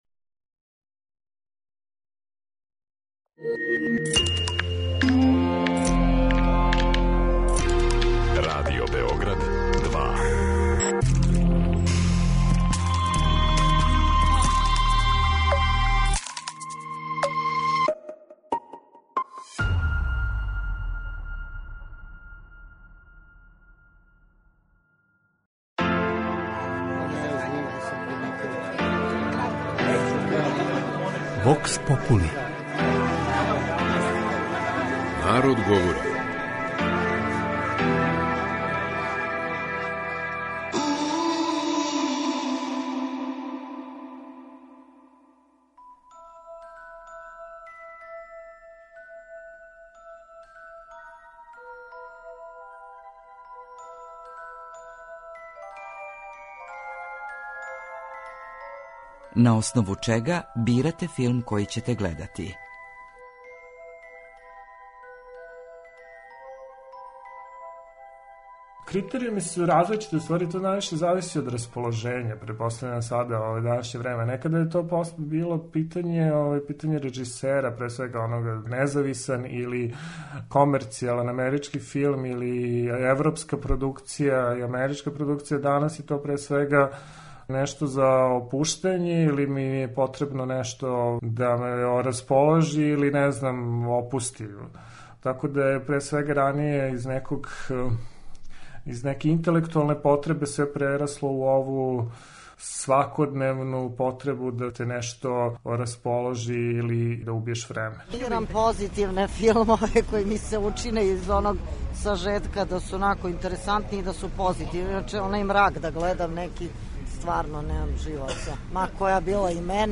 vox populi.mp3